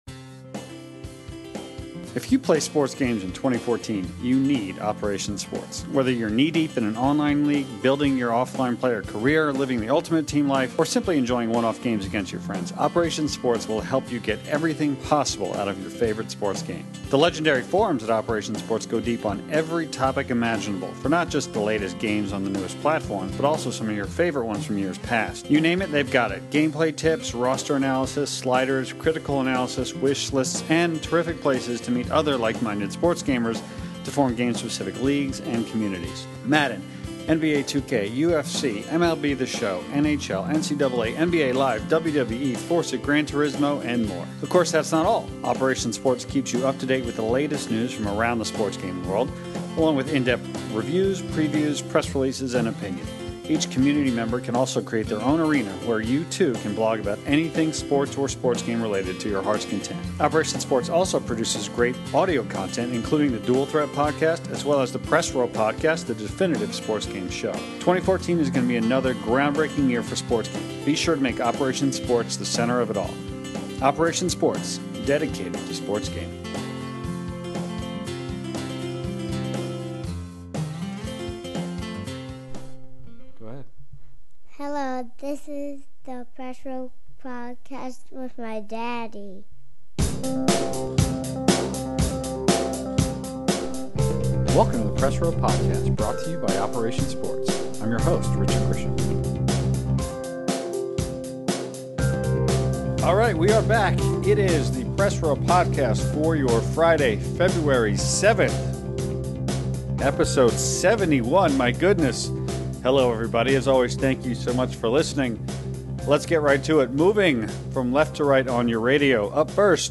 Press Row Podcast - Midwinter Roundtable
Up first, our crew goes around the table talking about what sports game and mode they’re playing the most now.
After that, we look at the lack of Olympic video games on the horizon of the Sochi Winter Games. Finally, we take a number of great listener questions about a series of topics.